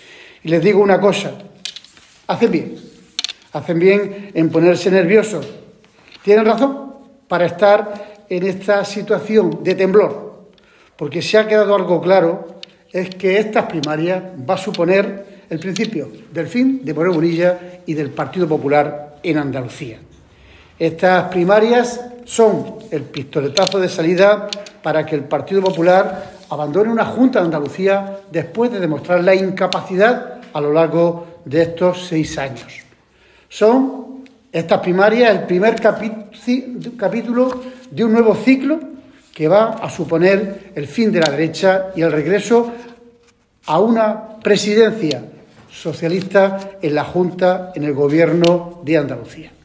En rueda de prensa, Reyes manifestó que “la grandeza de este proceso de democracia interna” del PSOE para elegir a su secretario general “tiene muy desquiciados a los responsables del PP”, puesto que el propio Moreno Bonilla “ha mandado a todos sus voceros a arremeter contra el PSOE”.
Cortes de sonido